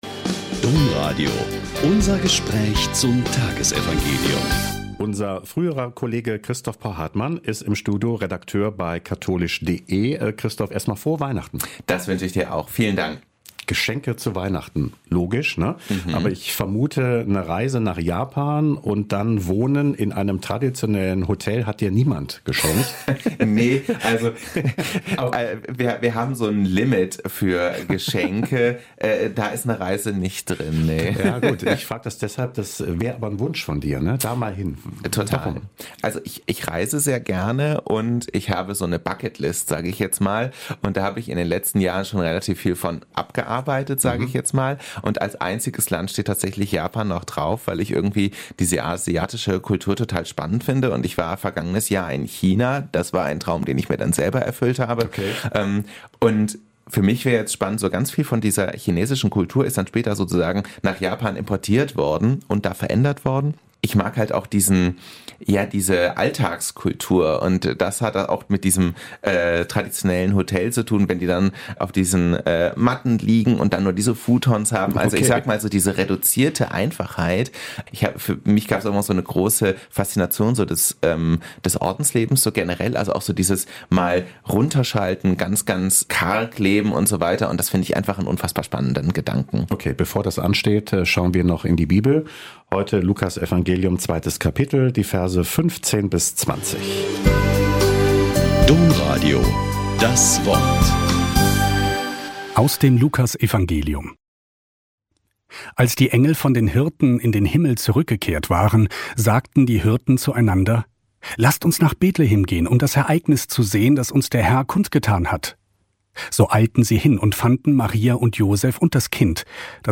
Lk 2,15-20 - Gespräch